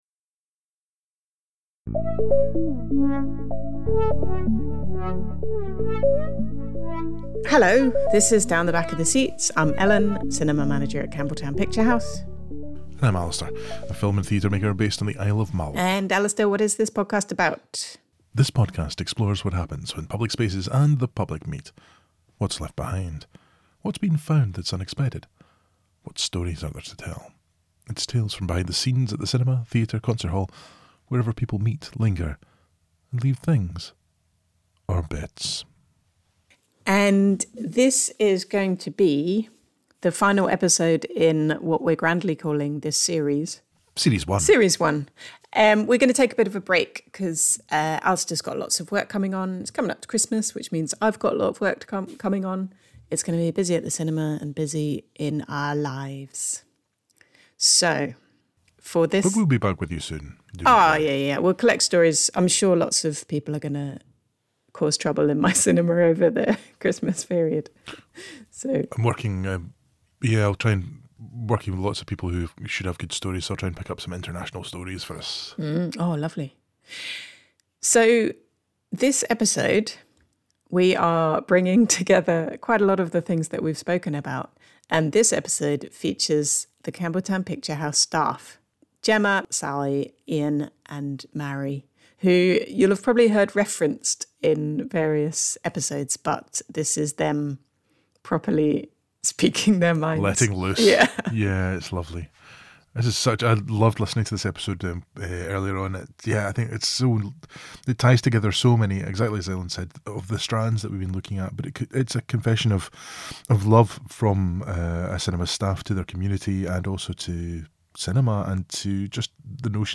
In the final episode of this season we hear from the staff of Campbeltown Picture House in a discussion ranging from things customers do that annoy us to things customers do that annoy us.